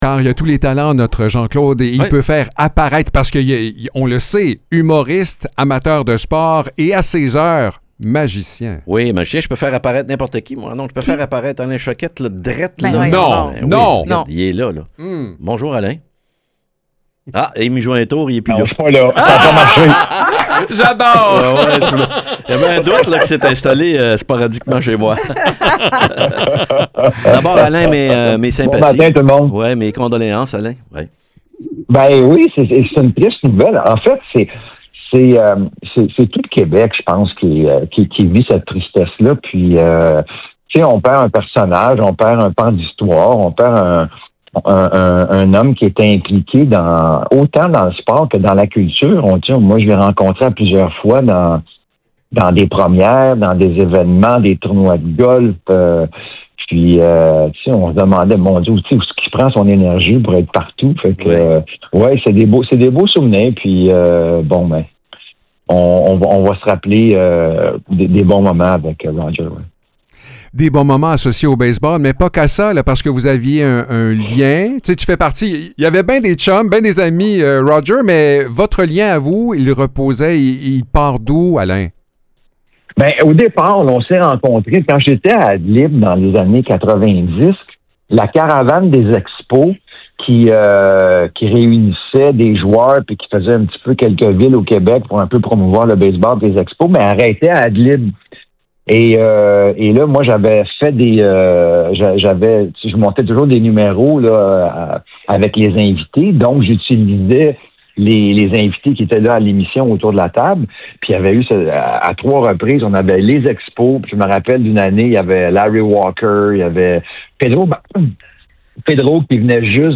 On discute avec le magicien Alain Choquette, qui partage avec nous de précieux souvenirs liés au regretté Rodger Brulotte.